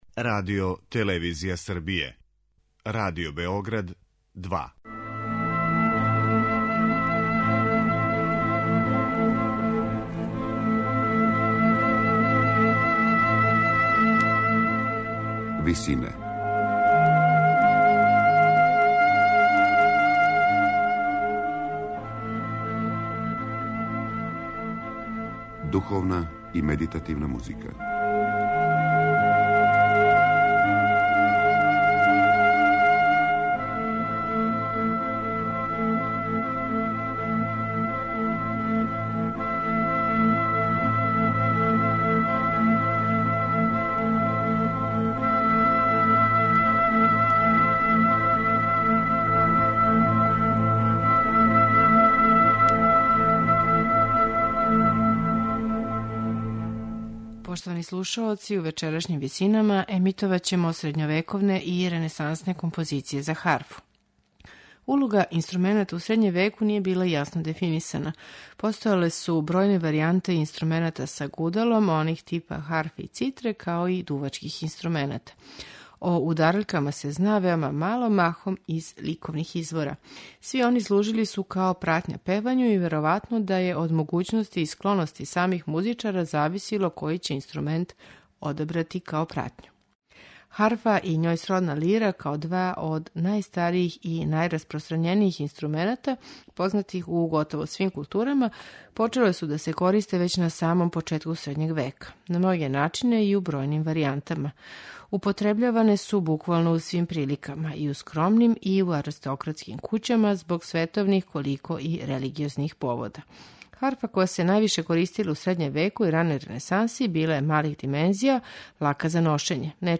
Ренесансна харфа
духовне напеве и баладе средњовековних и ренесансних аутора